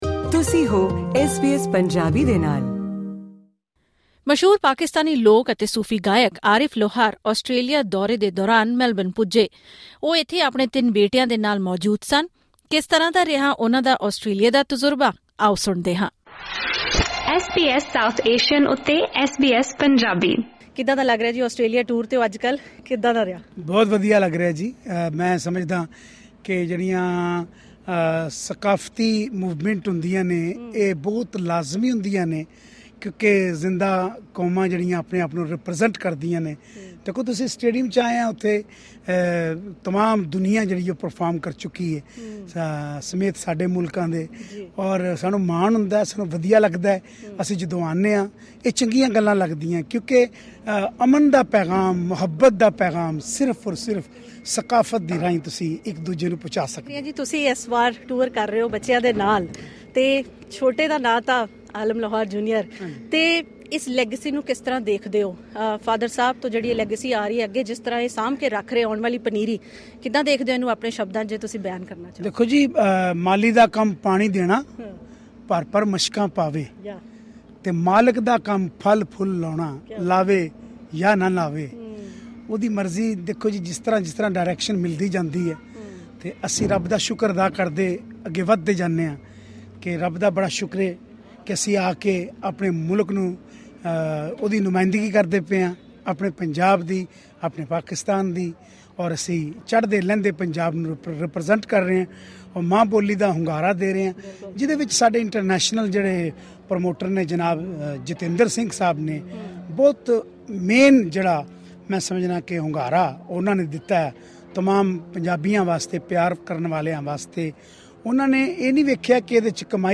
ਮਸ਼ਹੂਰ ਪਾਕਿਸਤਾਨੀ ਸੂਫ਼ੀ ਗਾਇਕ ਆਰਿਫ਼ ਲੋਹਾਰ ਦੇ ਆਸਟ੍ਰੇਲੀਆ ਦੌਰੇ ਦੌਰਾਨ ਖਾਸ ਗੱਲਬਾਤ